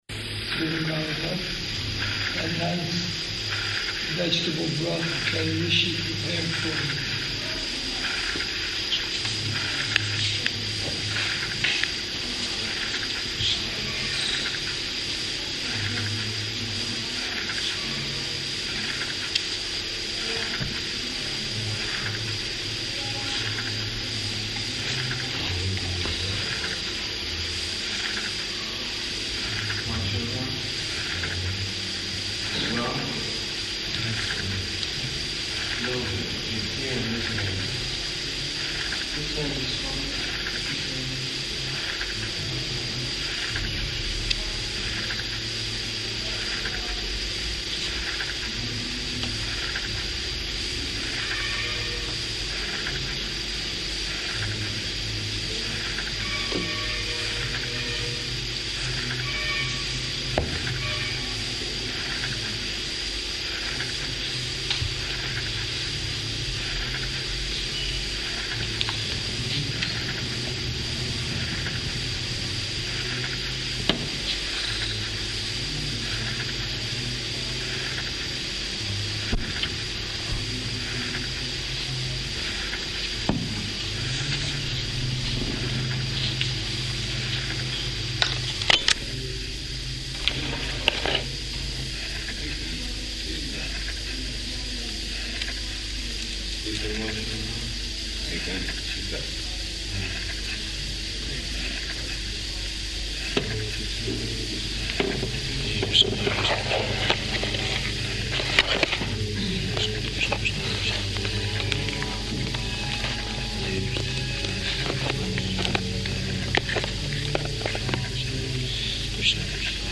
Room Conversation
-- Type: Conversation Dated: October 25th 1977 Location: Vṛndāvana Audio file